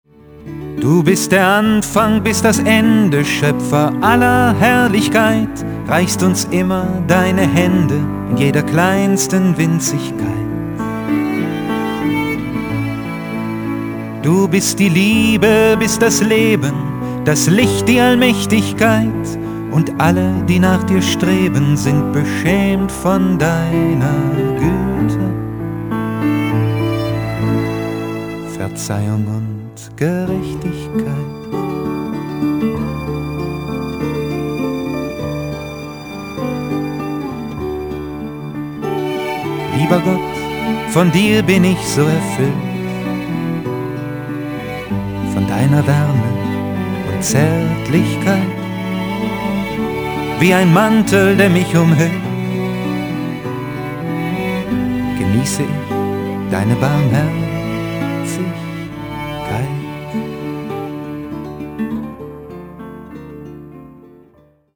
Die anspruchsvoll arrangierten Lieder gehen unter die Haut.